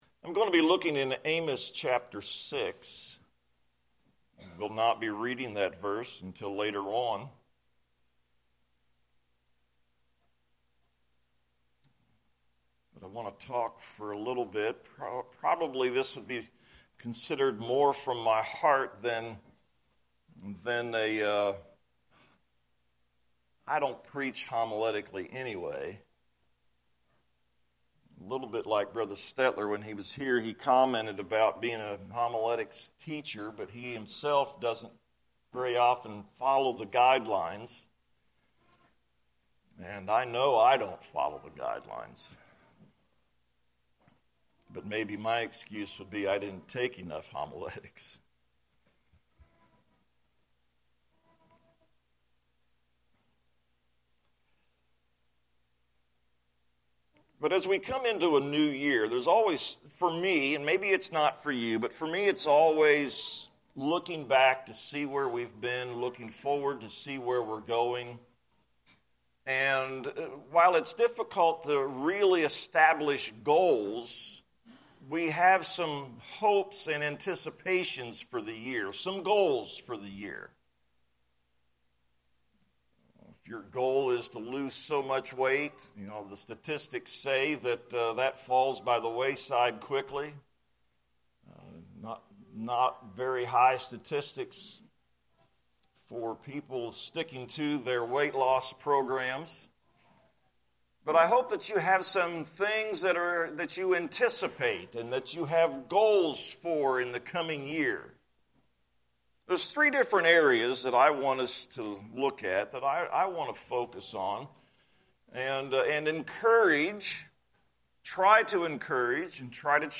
Sermons – Weisbach Church podcast